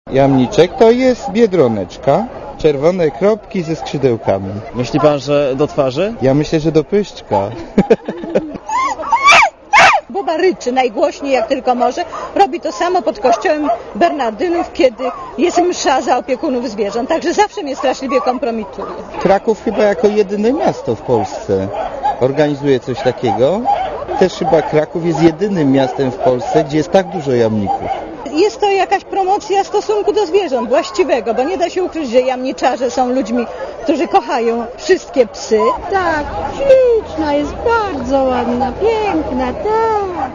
Relacja reportera Radia ZET Były konkursy.